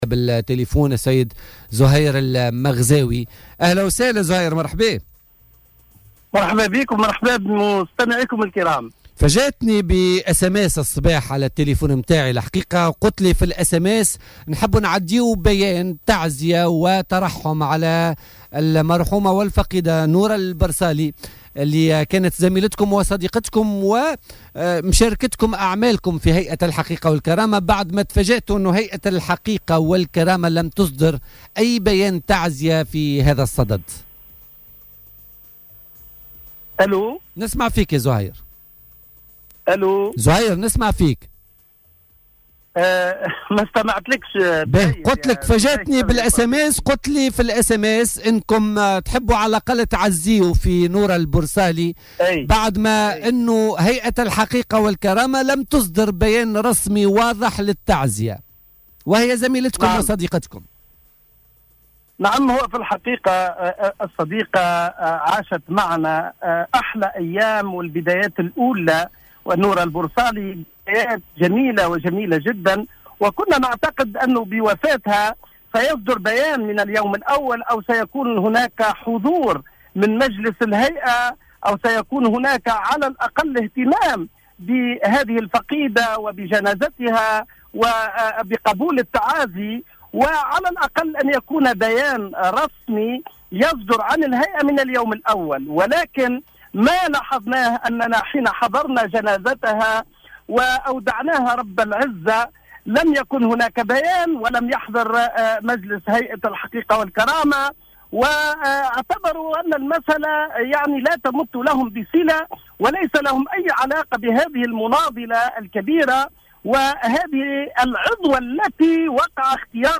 وفي اتصال هاتفي بـ "الجوهرة أف أم" ضمن برنامج "بوليتيكا"